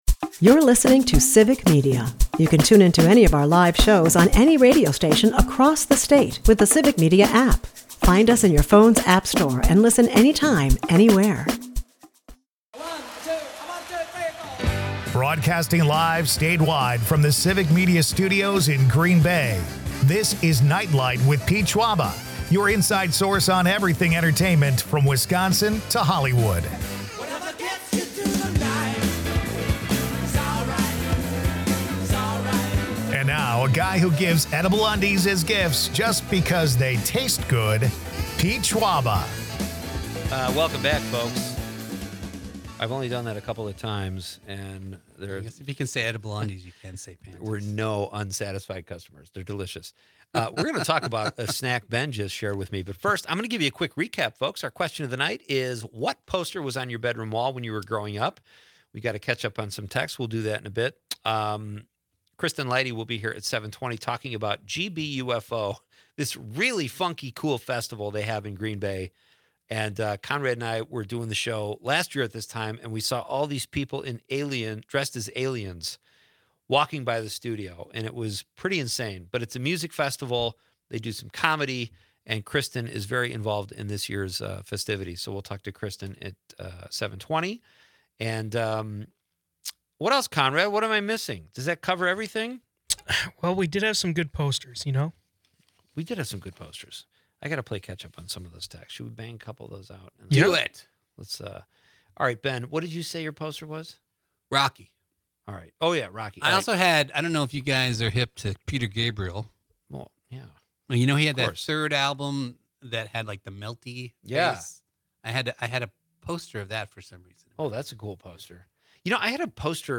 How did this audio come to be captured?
A lively mix of nostalgia, entertainment, and local culture.